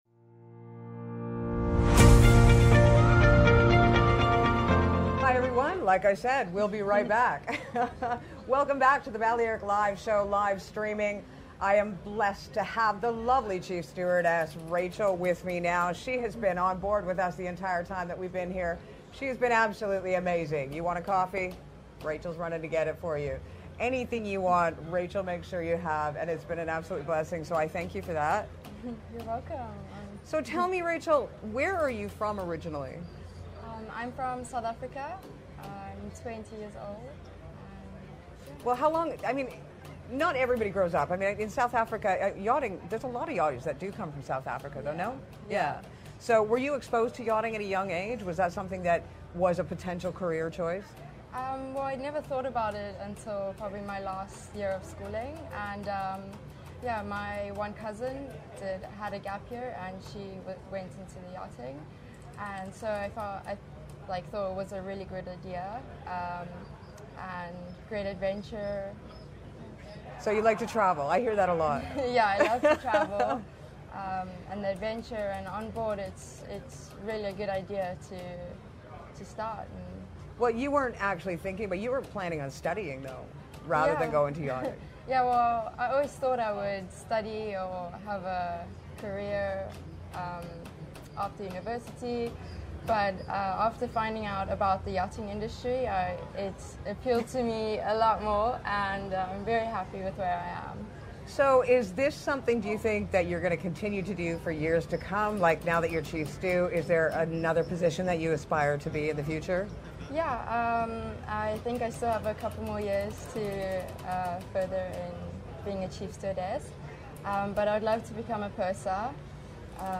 If you missed some of the great interviews that were had during The Balearic Yacht Show, not to worry, we will be bringing you a few of them here!